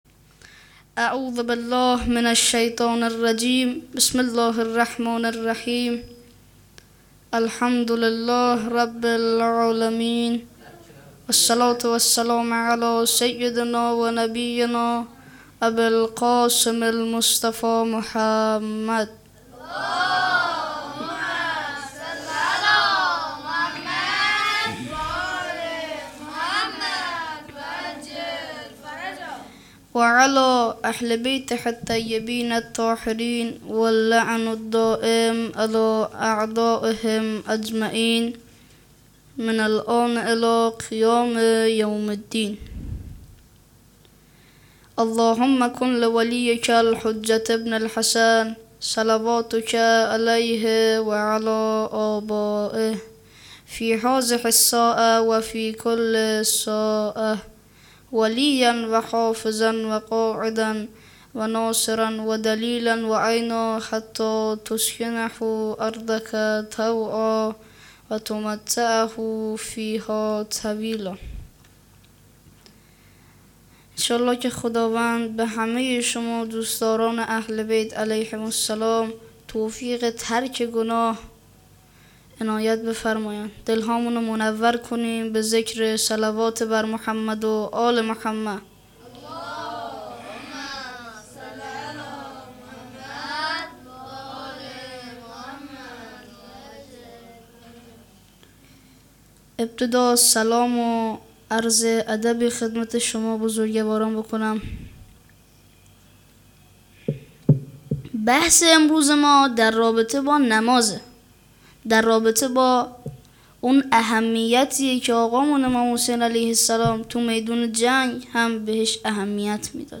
سخنرانی شب اول محرم
منتخب مراسم دهه اول محرم۹۹